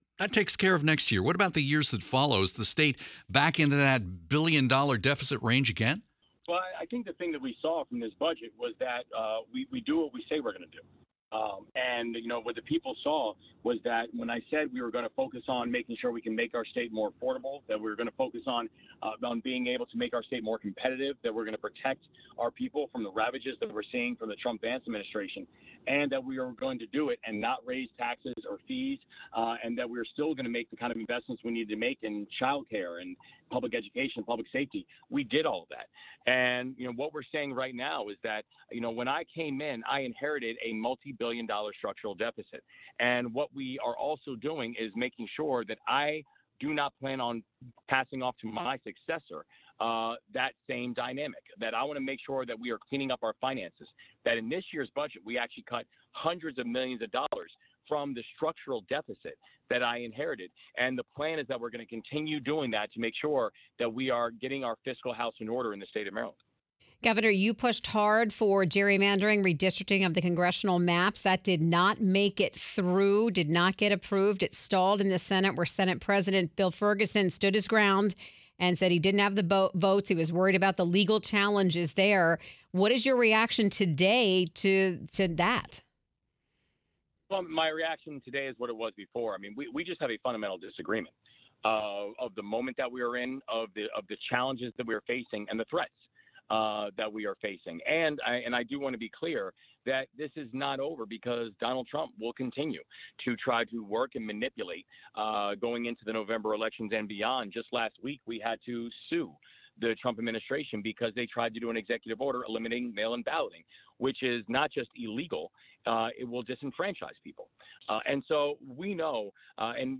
This interview has been lightly edited for clarity.